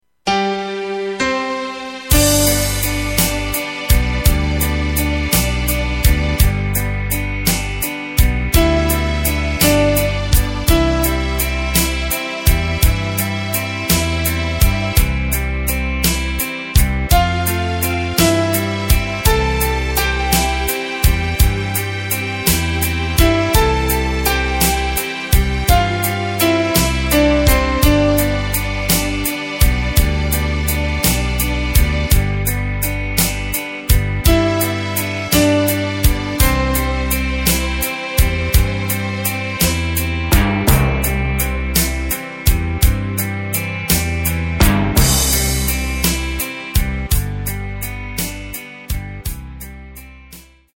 Tempo:         65.00
Tonart:            C
Instrumental für Trompete!
Playback mp3 Demo